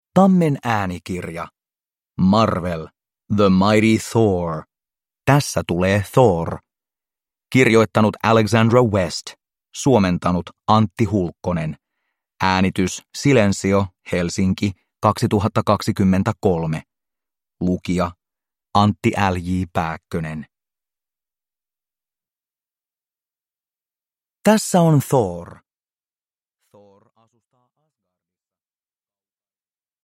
Tässä tulee Thor – Ljudbok – Laddas ner